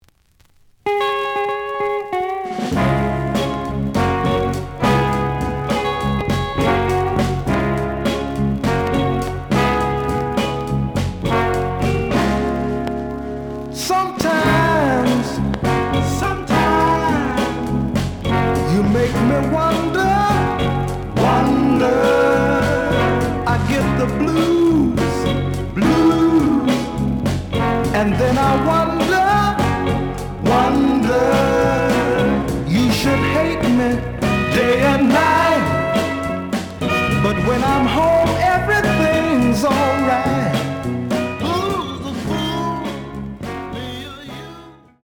The audio sample is recorded from the actual item.
●Genre: Soul, 60's Soul
Some click noise on beginning of both sides due to a pop.)